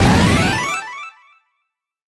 Sound clip from DS Waluigi Pinball in Mario Kart 8 Deluxe